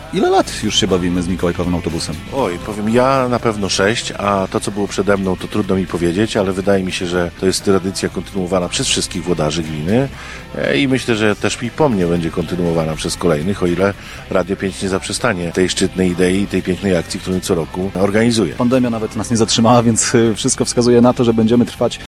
Samorządowcy jako pierwsi pojawili się w Mikołajkowym Autobusie Radia 5 w Ełku.
Jako pierwszy w Mikołajkowym Autobusie Radia 5 pojawił się dziś wójt Gminy Ełk Tomasz Osewski.